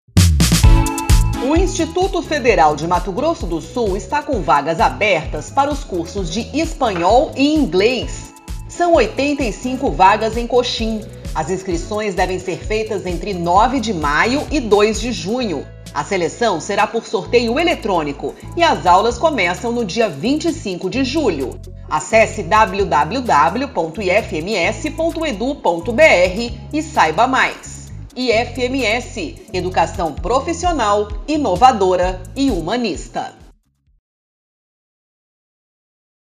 Spot - Cursos de idiomas para o 2º semestre de 2022 em Coxim